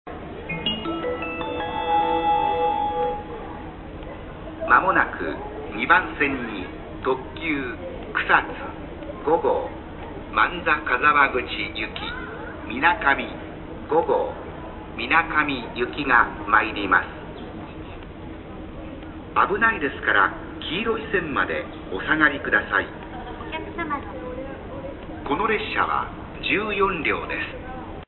接近放送「草津-水上5号」 「草津-水上5号」の接近放送です。